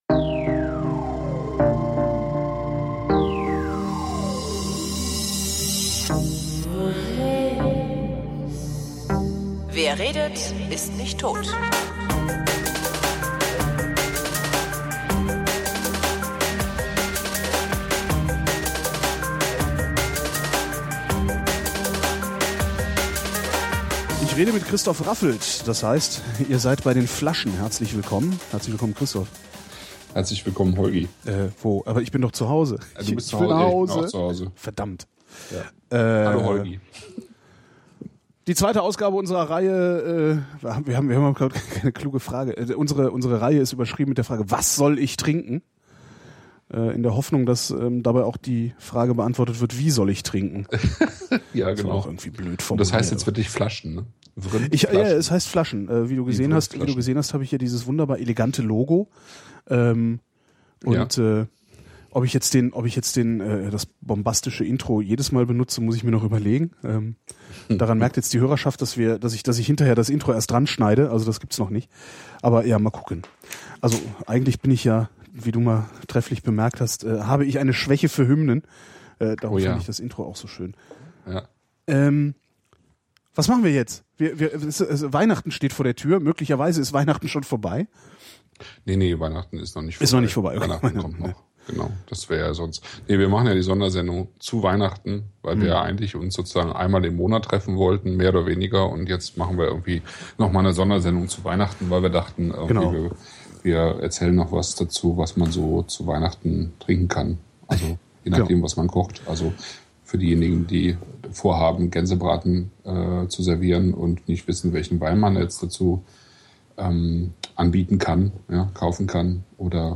Dummerweise bin ich aber schon ziemlich knülle, weil ich so doof war, keinen Spucknapf neben mir stehen zu haben. Und Alkohol geht ja bekanntlich auf die Konzentration.